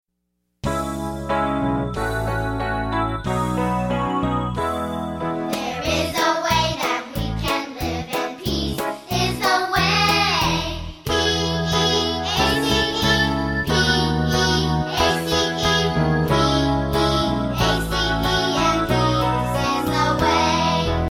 friendship songs